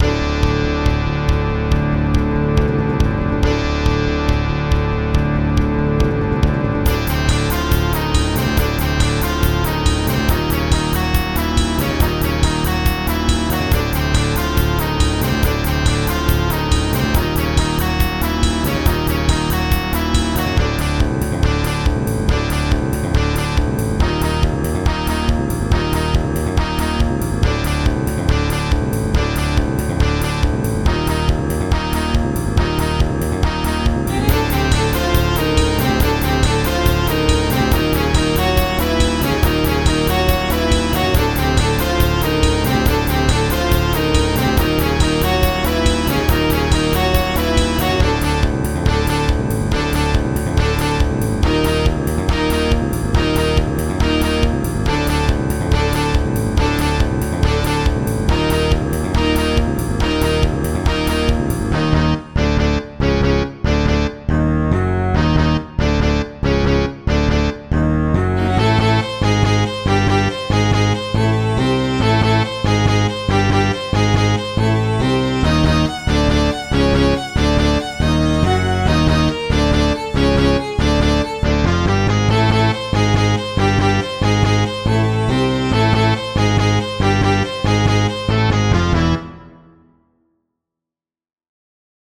Another one of my attempts at a darker MIDI track for battles or other action sequences. I really like how octaves sound with the Distortion Guitar, especially with the Roland SC-55 soundfont that I used to export this song.